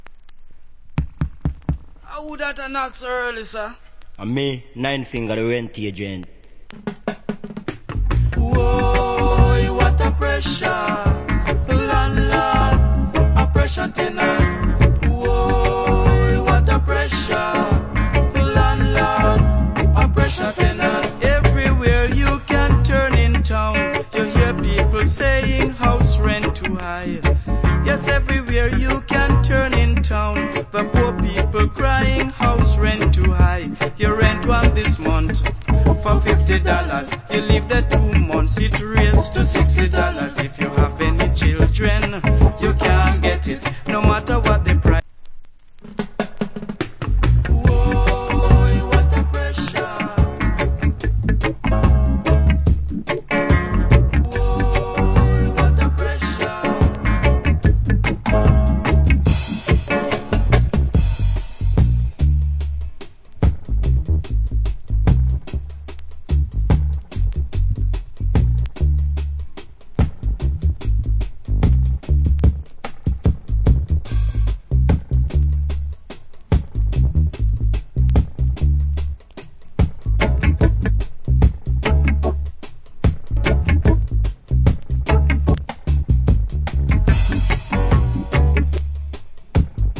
コメント Good Reggae Vocal.